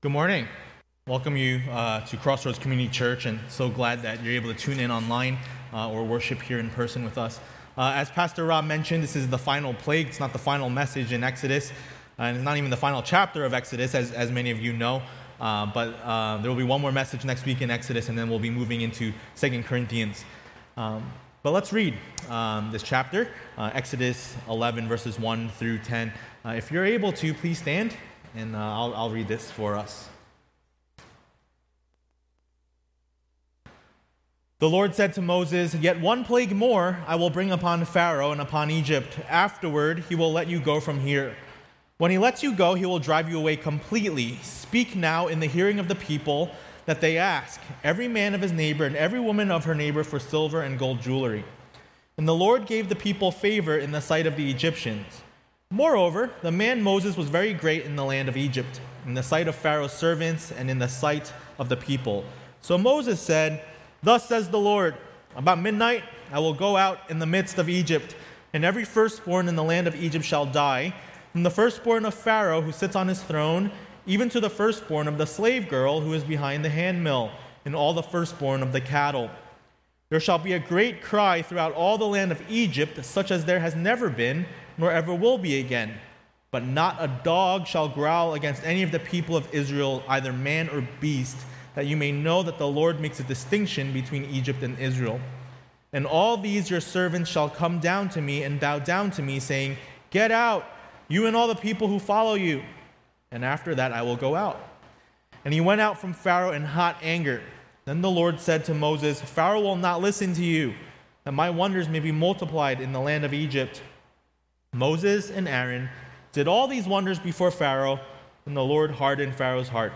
A message from the series "Sojourners: Exodus ."